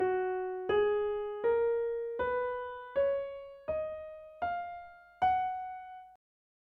F# Major
Ex-2-F-Major.mp3